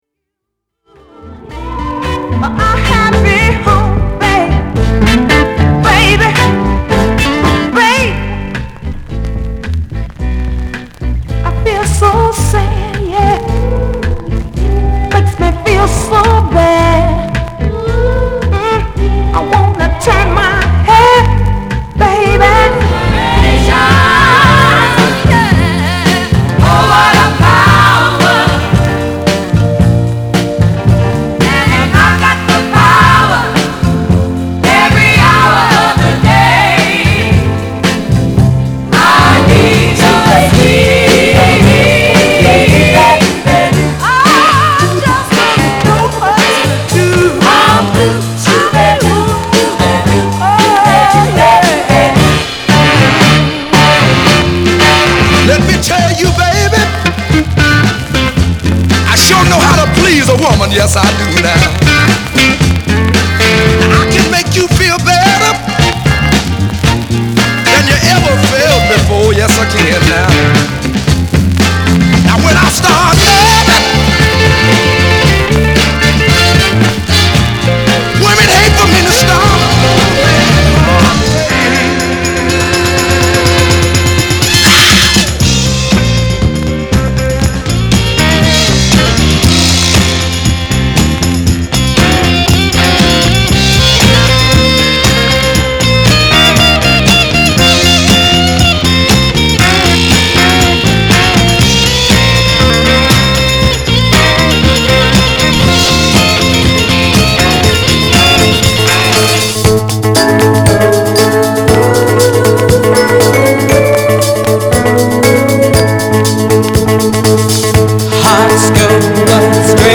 /盤質/両面やや細かい傷あり再生良好/US PRESS